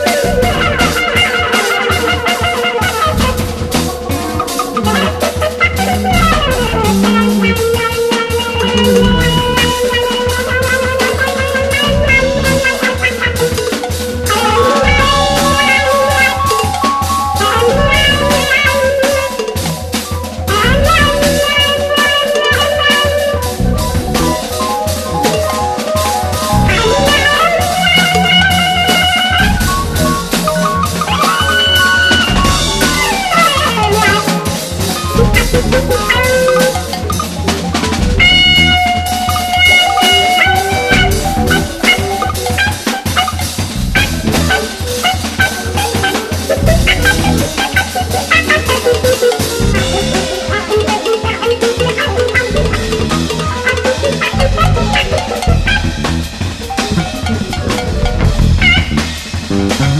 ROCK / 70'S
短い音の連なり、短編曲で構成されたSIDE-1。宅録感溢れる仕上がりで、胸のうちの切なさを吐露したSIDE-2。